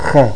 P   kh#